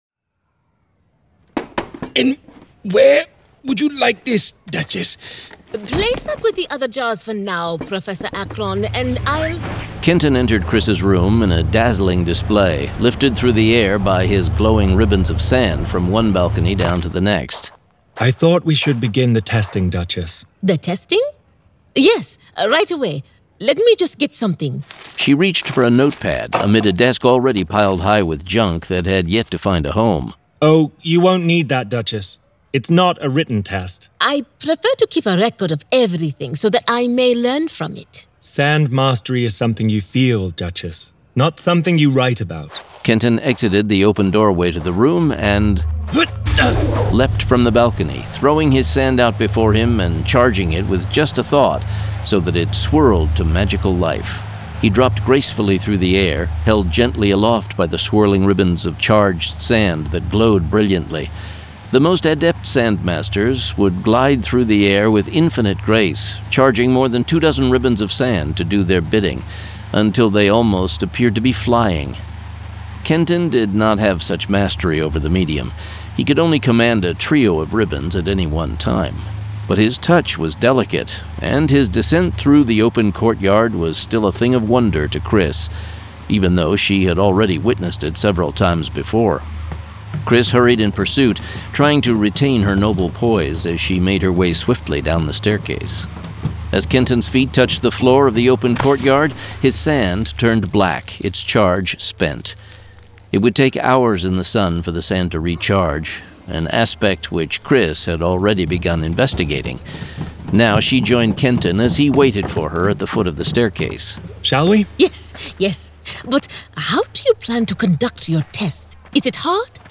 Full Cast. Cinematic Music. Sound Effects.
For the first time ever in audio! GraphicAudio and Brandon Sanderson are proud to present WHITE SAND produced with a full cast of actors, immersive sound effects and cinematic music.